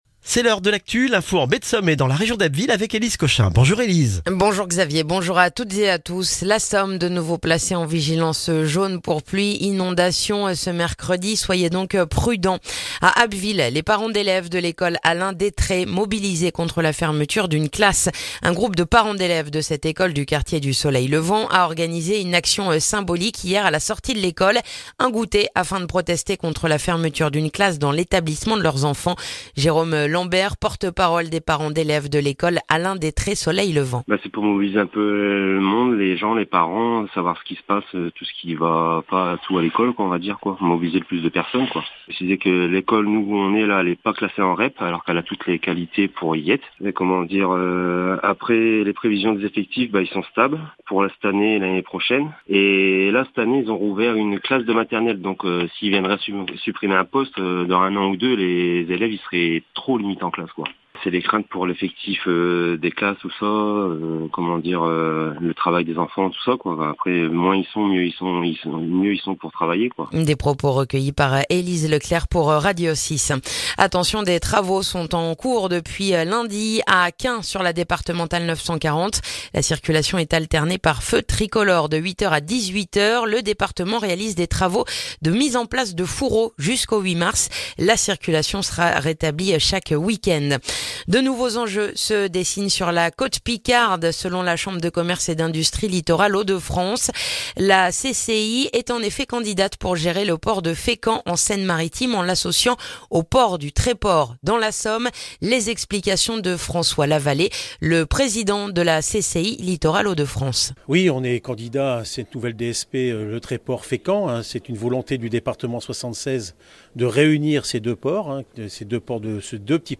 Le journal du mercredi 7 février en Baie de Somme et dans la région d'Abbeville